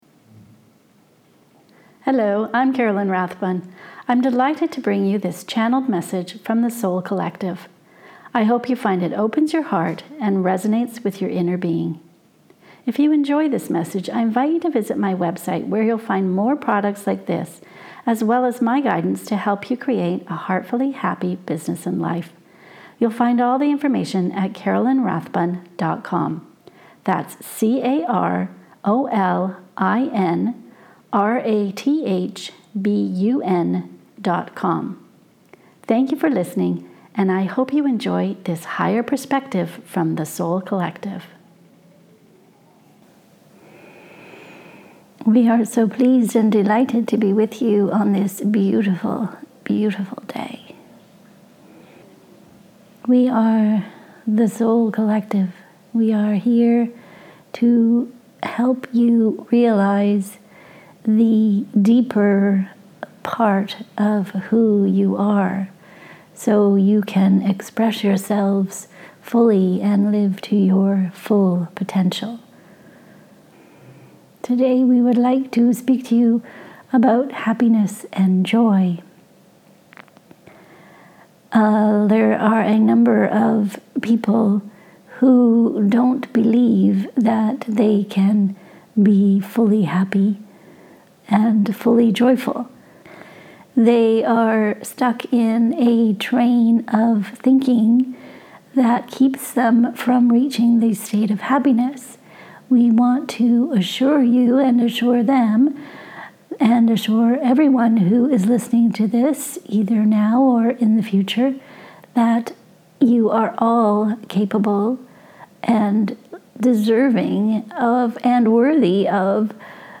PLUS, includes a guided practice to fill yourself with love.